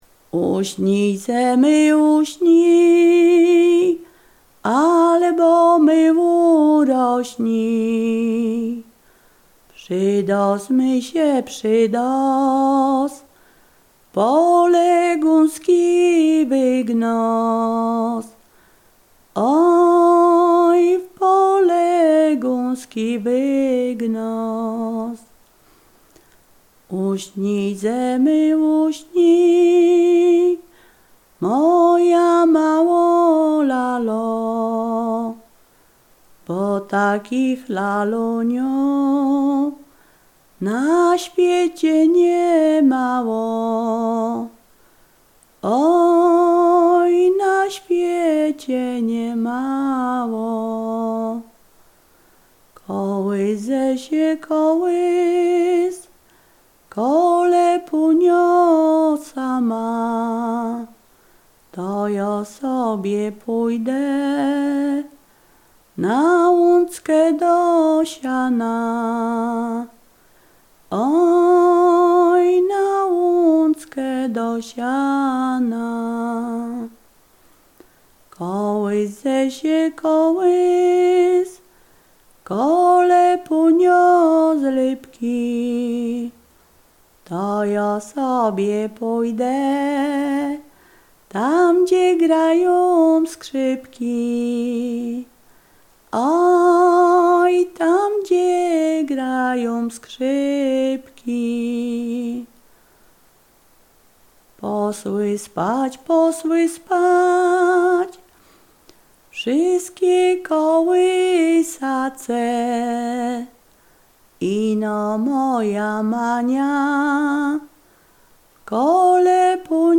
Ziemia Radomska
Kołysanka
kołysanka folklor dziecięcy narodziny obrzędy domowe